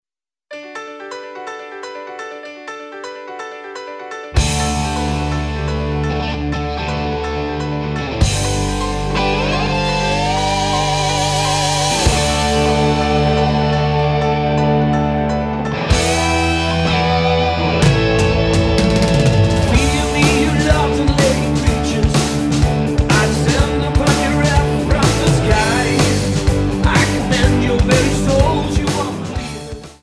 --> MP3 Demo abspielen...
Tonart:D Multifile (kein Sofortdownload.
Die besten Playbacks Instrumentals und Karaoke Versionen .